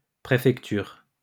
In France, a prefecture (French: préfecture, pronounced [pʁefɛktyʁ]